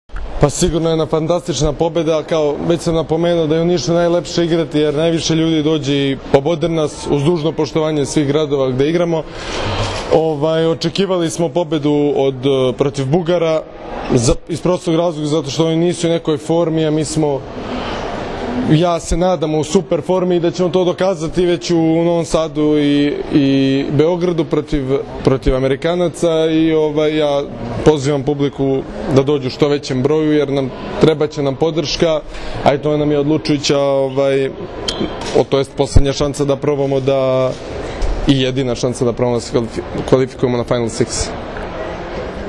IZJAVA UROŠA KOVAČEVIĆA